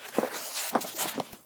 paper_scroll.ogg